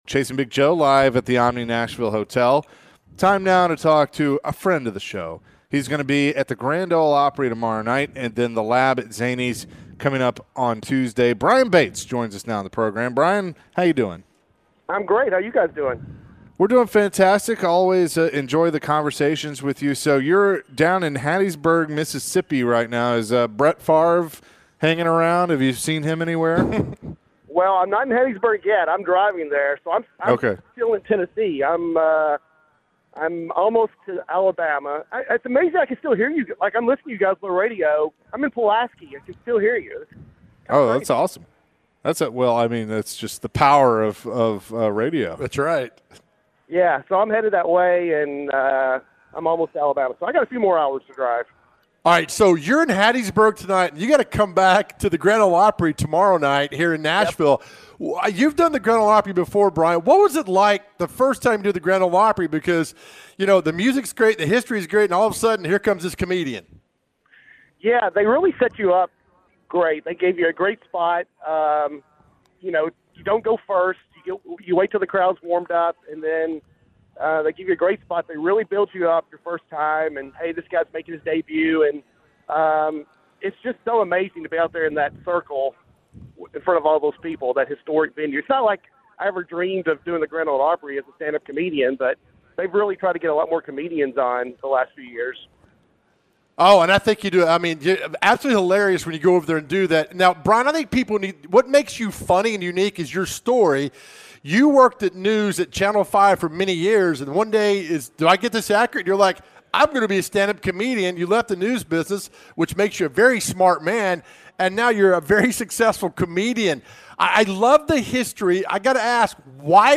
Hilarious comedian